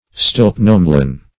Search Result for " stilpnomelane" : The Collaborative International Dictionary of English v.0.48: Stilpnomelane \Stilp*nom"e*lane\, n. [Gr. stilpno`s shining + me`las, -anos, black.]
stilpnomelane.mp3